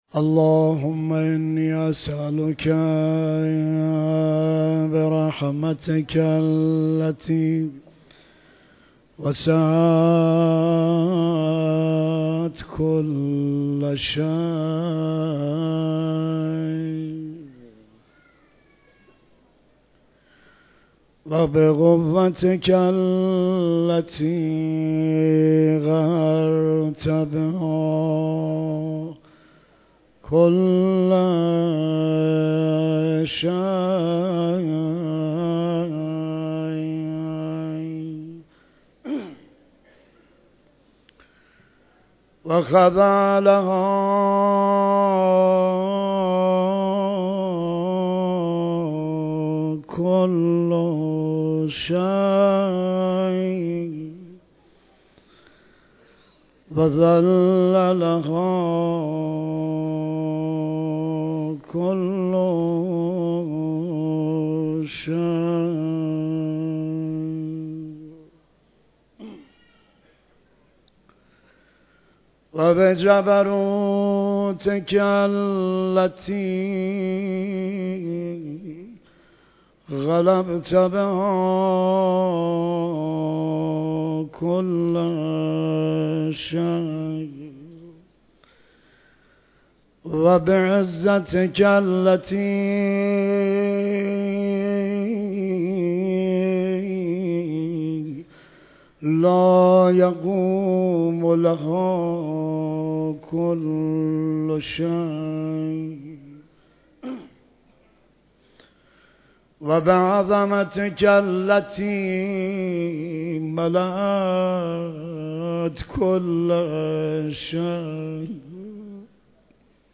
مراسم شام غریبان شهادت حضرت صدیقه کبری سلام الله علیها برگزار شد
مداحی